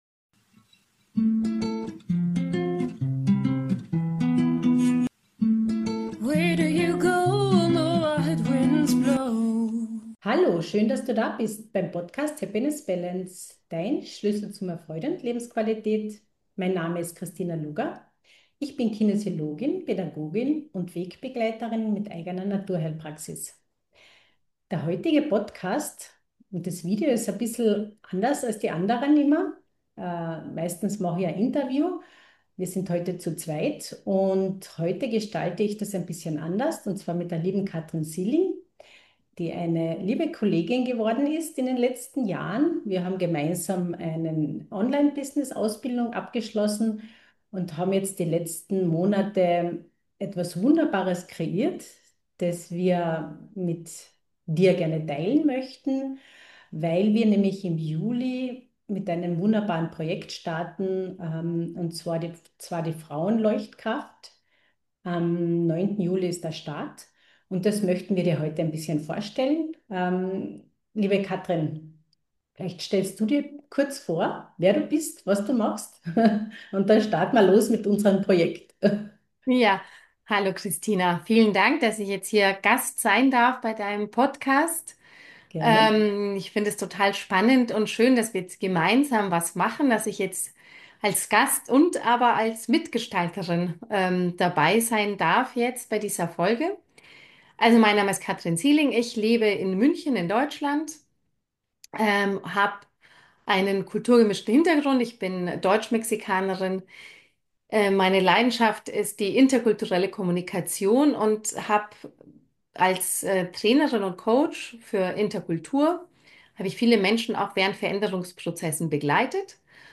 Ein inspirierendes und zugleich kraftvolles Gespräch.